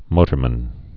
(mōtər-mən)